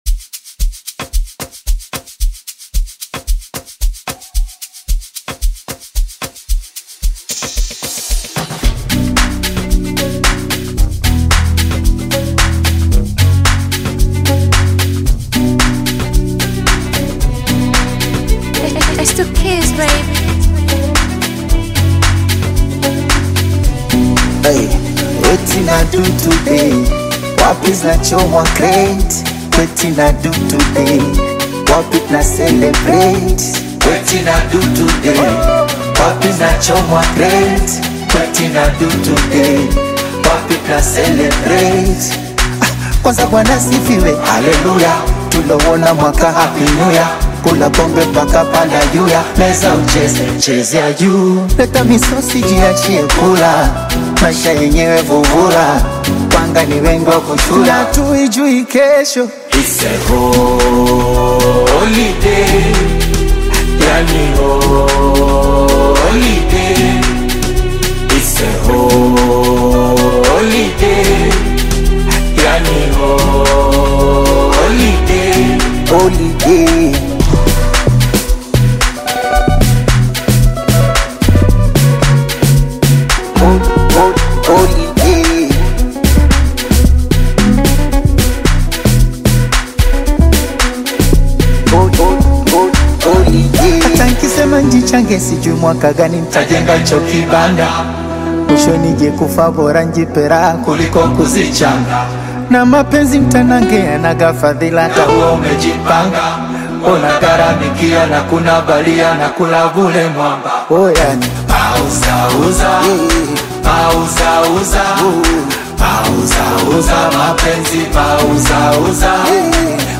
upbeat Amapiano-flavored single